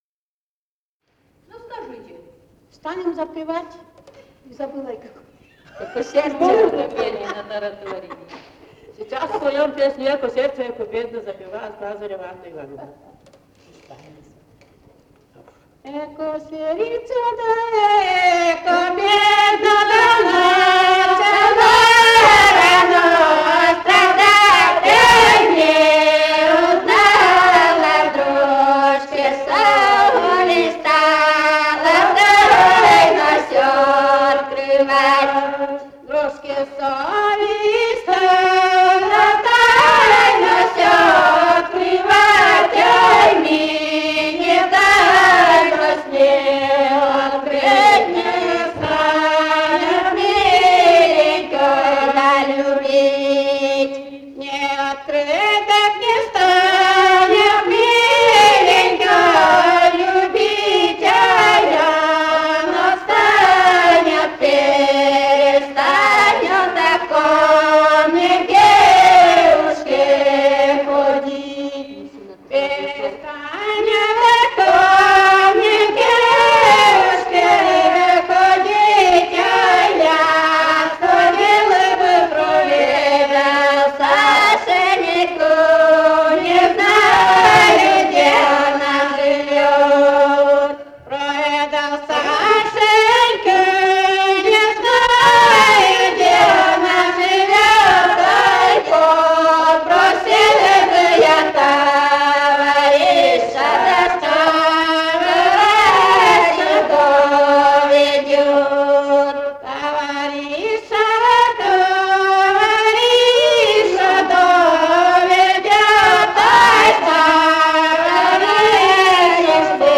«Эко сердцё, да эко бедно» (лирическая).